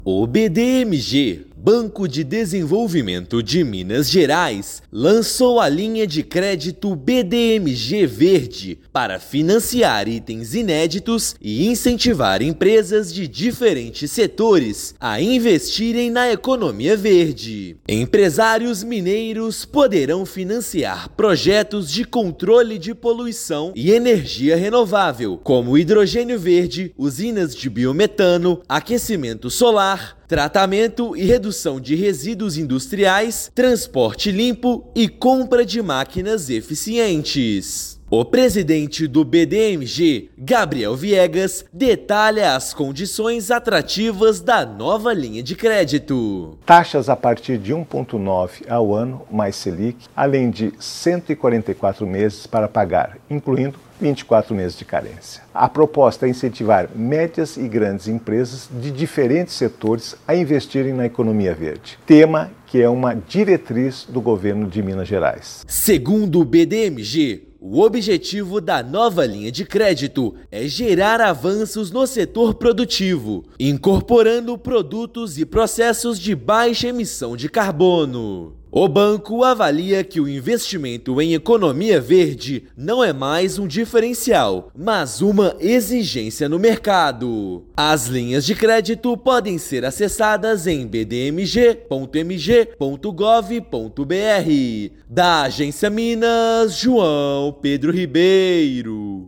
Com prazo de até 144 meses para pagar, “BDMG Verde” estimula empresários mineiros a incorporarem práticas sustentáveis em processos e produtos. Ouça matéria de rádio.